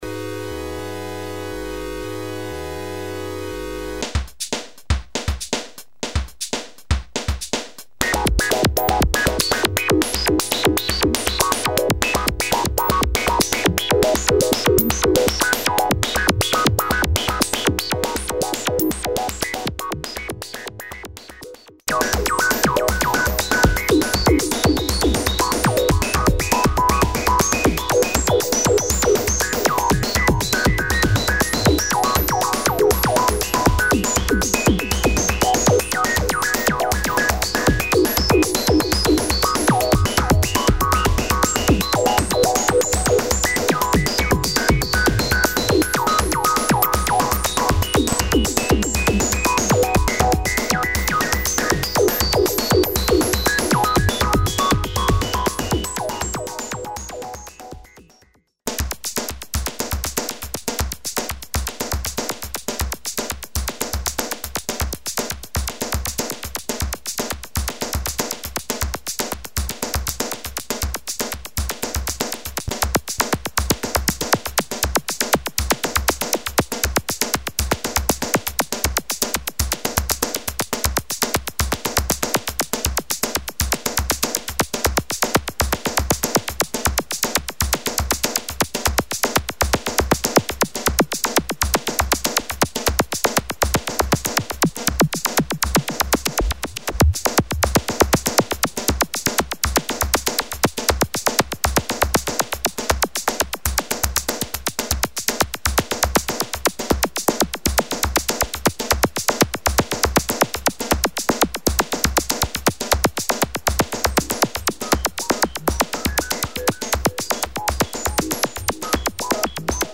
6. At the beginnig you hear a simple synth chord going into the seperate VCF1 input AND a simple drum loop going into the main input (at the same time), where the internal AUDIOTRIGGER generates synchronous triggers for the envelopes and the S&H, which is sampling a slow LFO sawtooth wave. Depending on the frequency relationship between the rhythmic tempo and the LFO different pattern are created. Finally a little delay & reverb are added to the main output, which is all you hear.